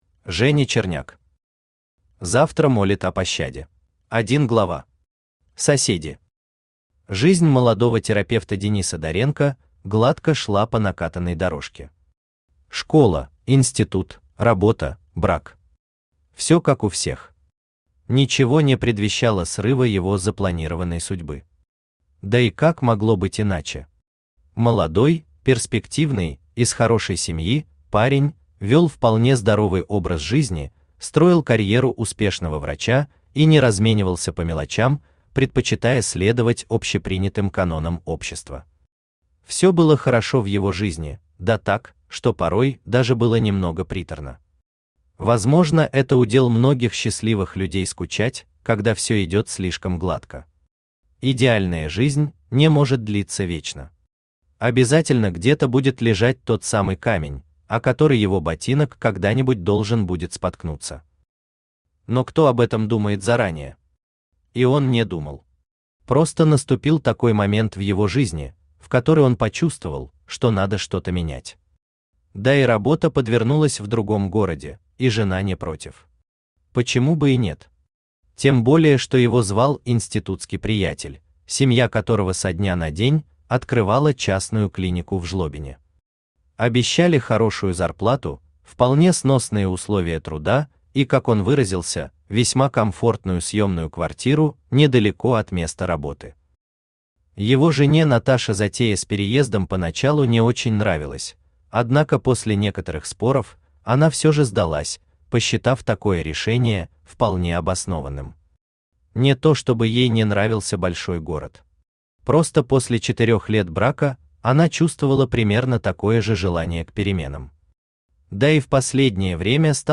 Аудиокнига Завтра молит о пощаде | Библиотека аудиокниг
Aудиокнига Завтра молит о пощаде Автор Женя Черняк Читает аудиокнигу Авточтец ЛитРес.